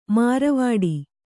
♪ māravāḍi